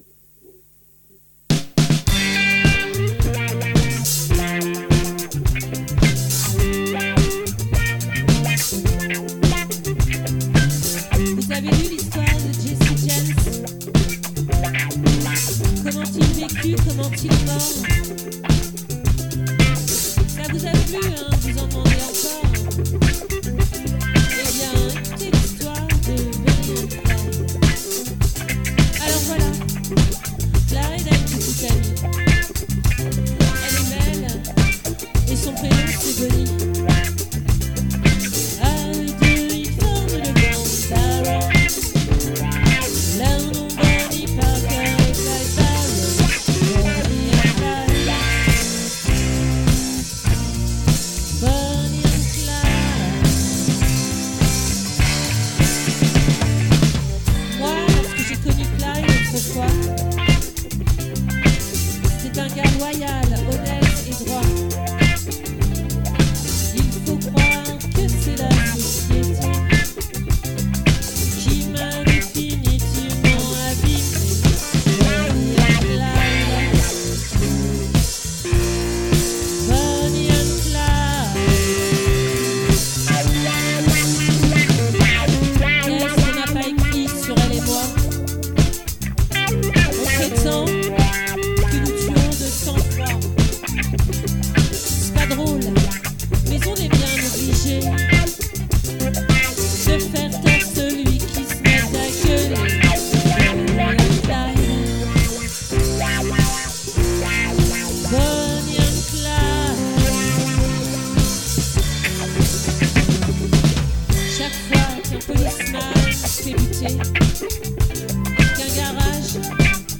🏠 Accueil Repetitions Records_2023_01_04_OLVRE